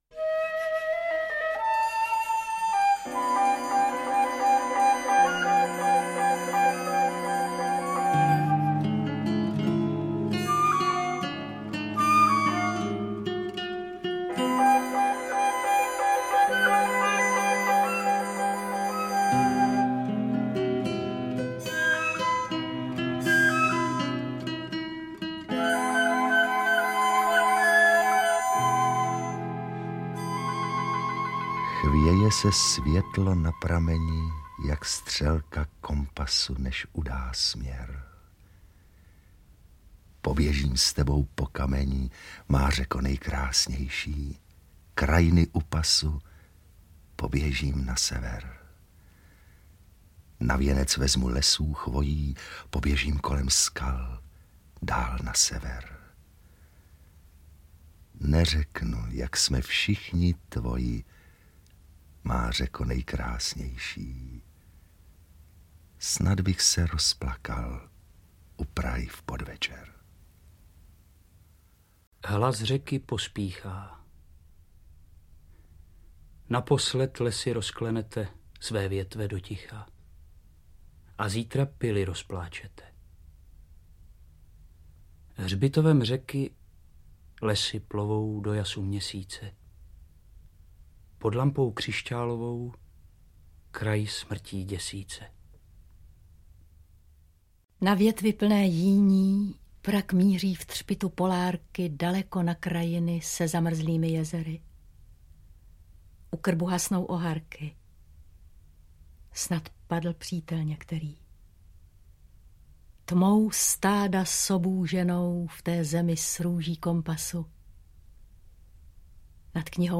• AudioKniha ke stažení František Branislav - Portrét básníka
Interpreti:  Jiří Ornest, Otakar Brousek st.